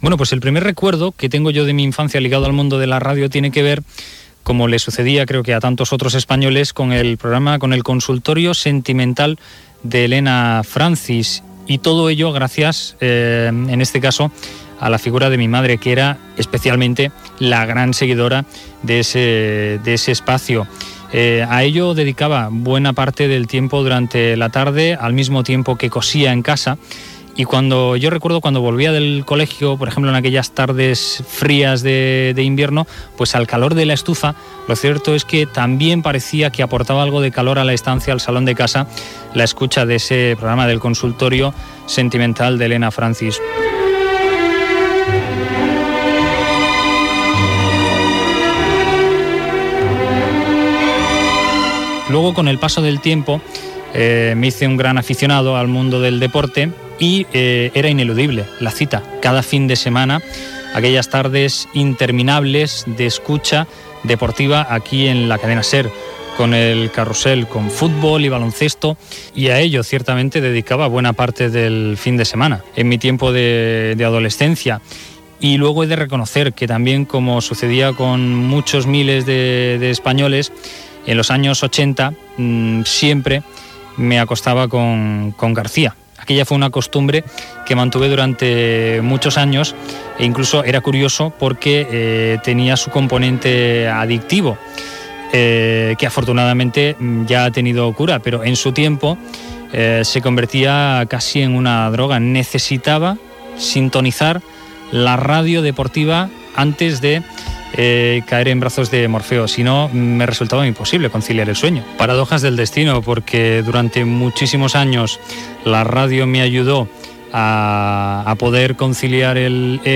Es pot escoltar un fragment del consultori Francis.
Divulgació